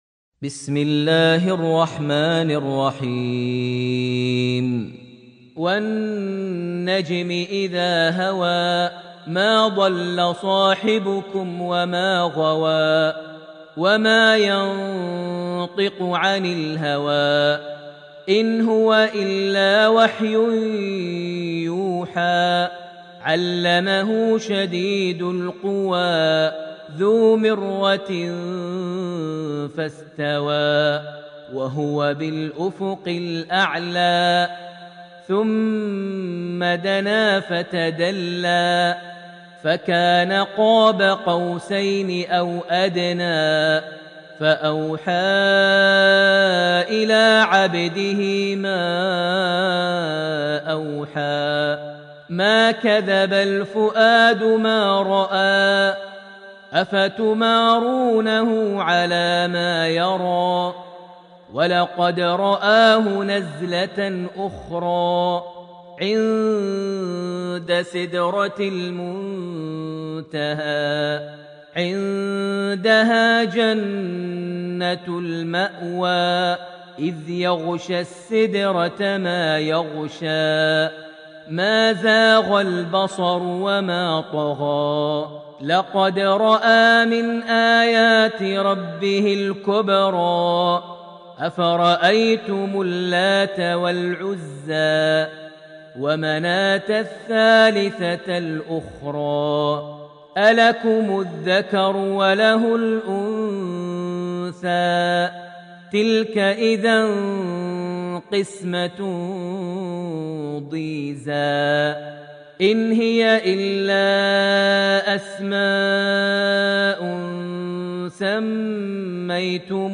surat Al-Najm > Almushaf > Mushaf - Maher Almuaiqly Recitations